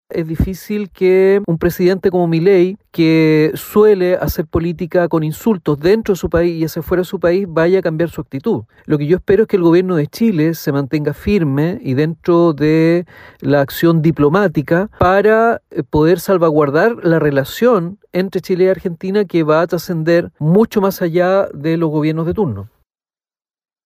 El diputado independiente e integrante de la comisión de Relaciones Exteriores, Félix González, indicó que espera que el gobierno de Chile no tenga la misma actitud que las autoridades trasandinas.